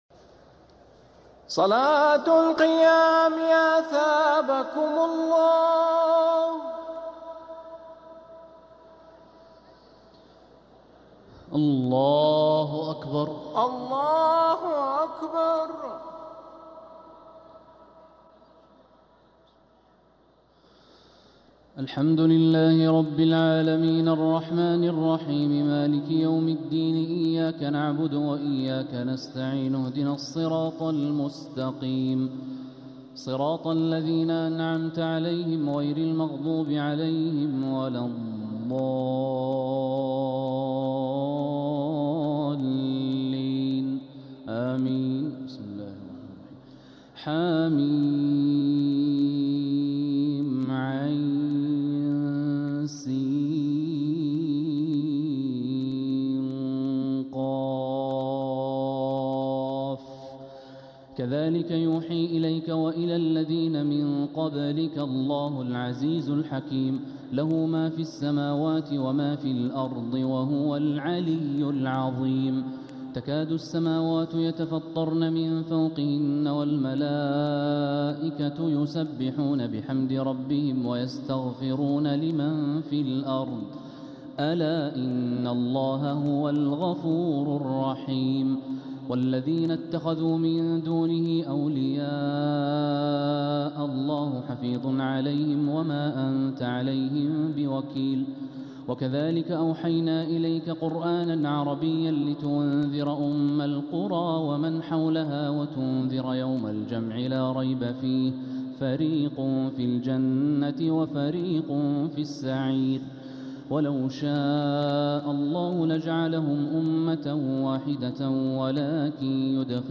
تراويح ليلة 26 رمضان 1446هـ سورة الشورى كاملة و الزخرف (1-45) | taraweeh 26th night Ramadan 1446H Surah Ash-Shuraa and Az-Zukhruf > تراويح الحرم المكي عام 1446 🕋 > التراويح - تلاوات الحرمين